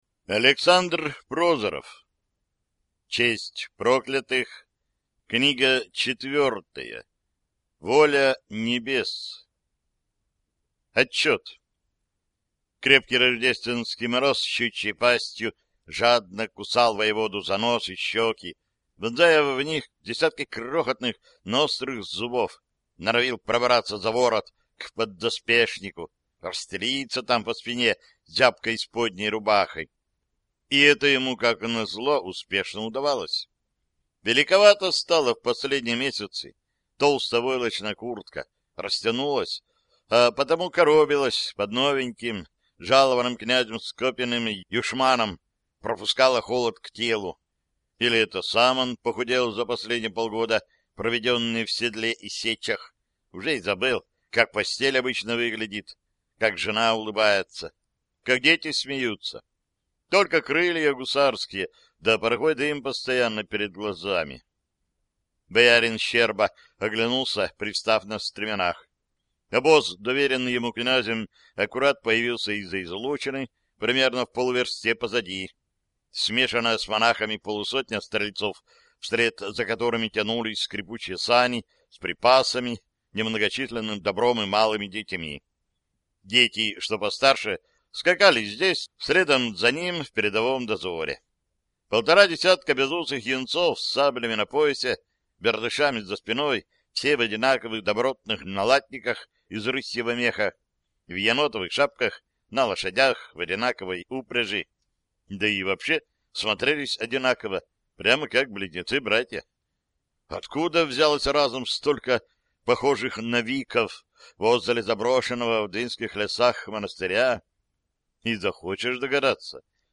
Аудиокнига Воля небес | Библиотека аудиокниг